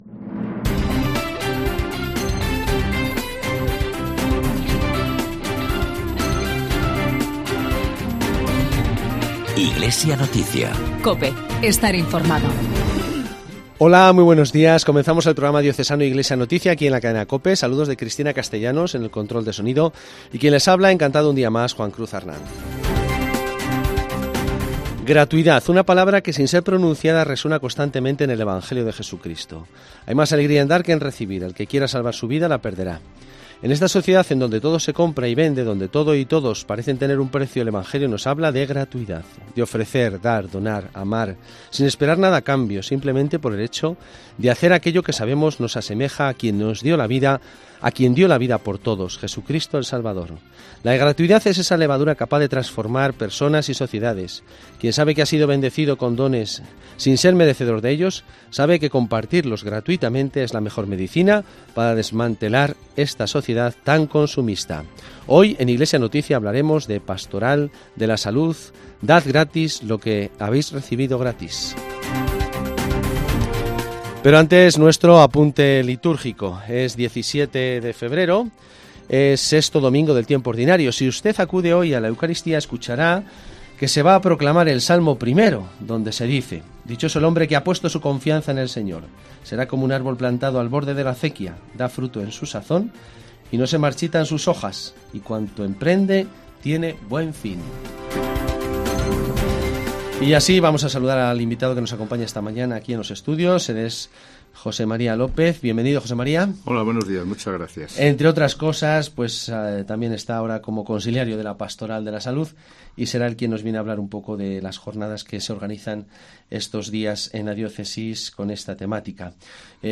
PROGRAMA RELIGIOSO